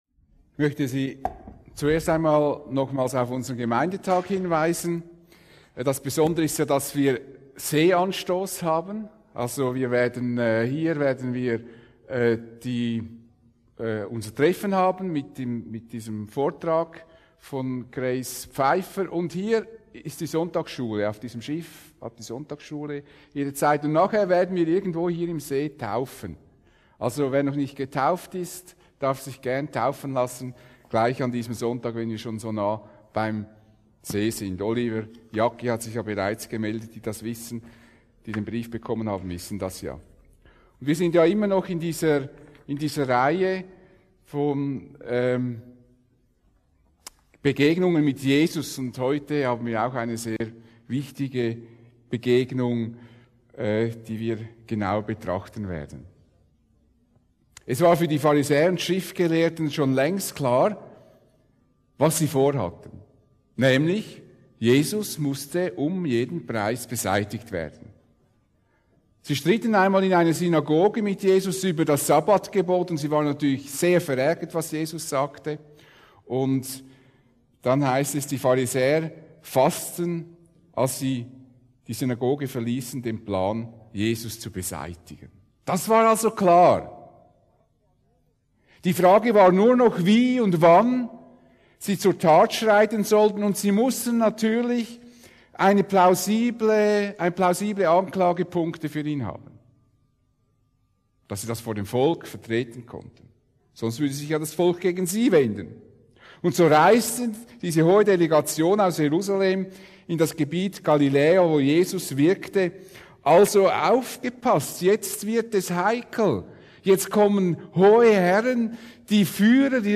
Predigt Audio Mt 15,1-20 Religiosität wird entlarvt